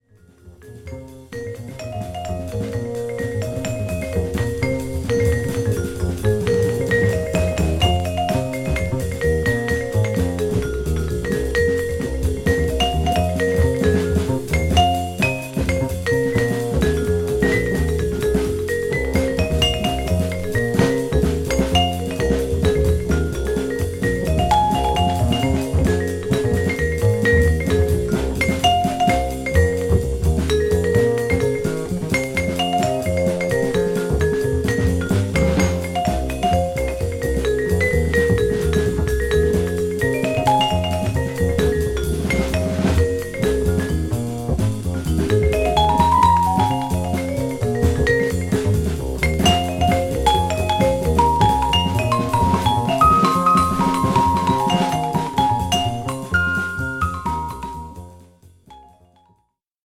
ホーム ｜ JAZZ